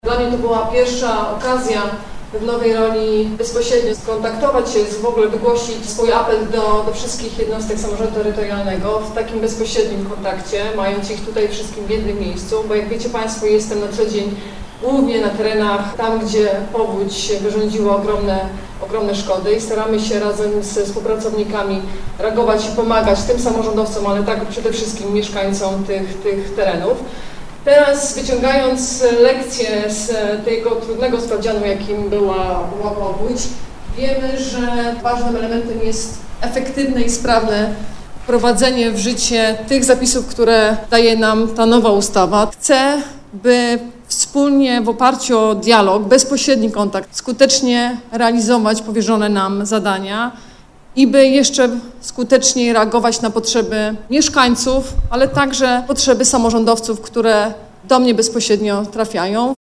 Wojewoda podziękowała za zaangażowanie i solidarność w związku z powodzią, która przeszła przez region we wrześniu. Usuwanie jej skutków potrwa kilka lat, należy już teraz wyciągać lekcje – tłumaczy.